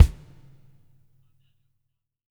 BW KICK 03-L.wav